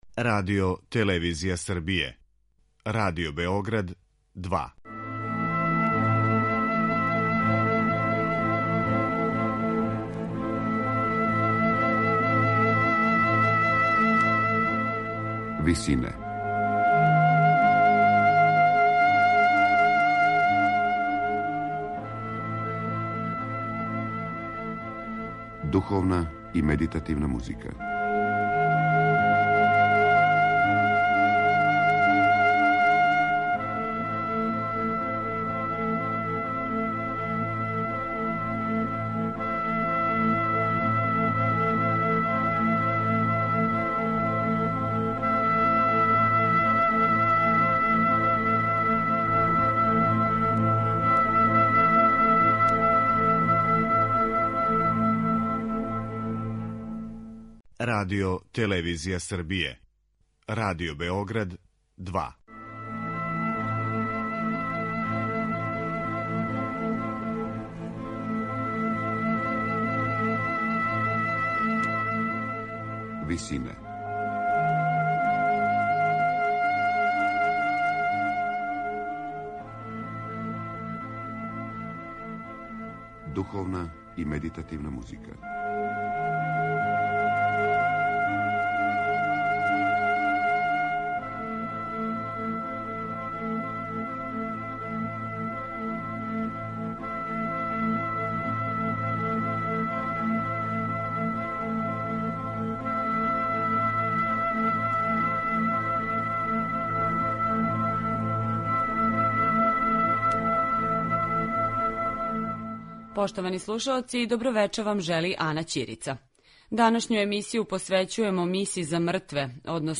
Међу бројним духовним остварењима француског барокног композитора Жана Жила Реквијем (1704/1705) за солисте, мешовити хор и оркестар jе у једном периоду био међу најцењенијим композицијама у Француској.